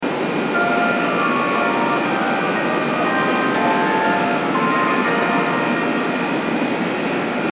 馬車道駅　Basyamichi Station ◆スピーカー：National天井型
2番線発車メロディー